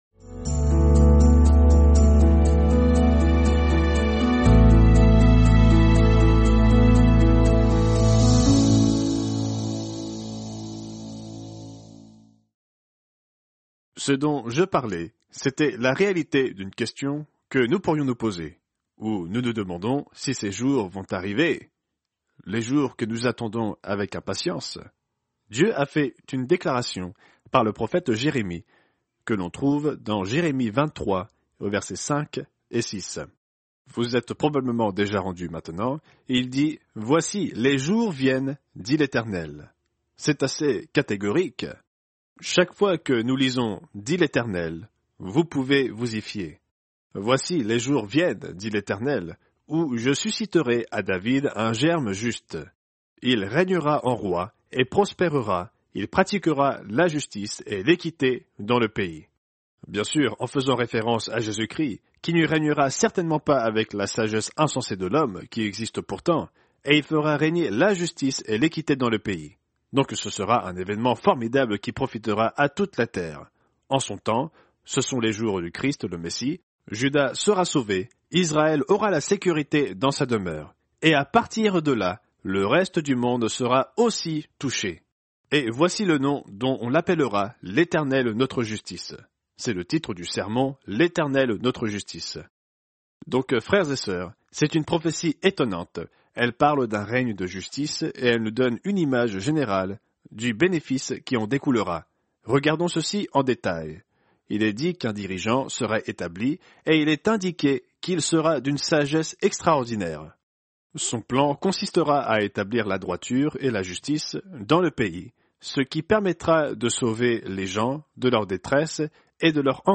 Fête des Tabernacles – 1er jour